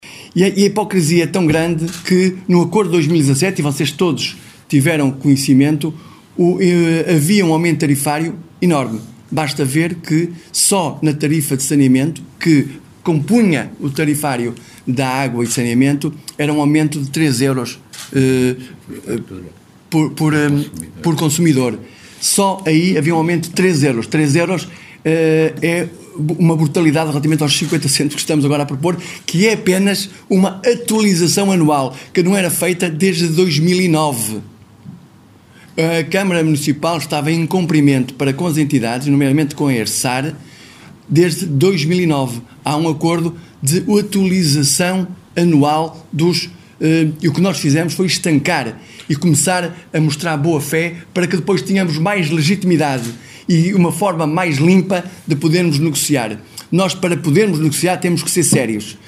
Mário Constantino, Presidente da Câmara Municipal de Barcelos, explicou, na manhã desta terça-feira, em conferência de imprensa, o aumento da tarifa do abastecimento da água, que será de 50 cêntimos, em média.